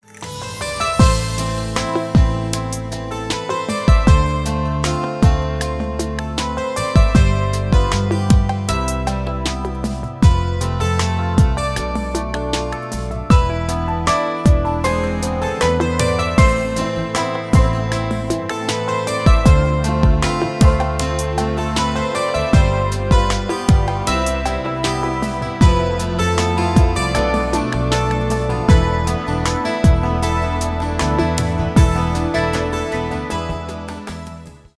без слов , красивая мелодия
инструментальные , романтические , поп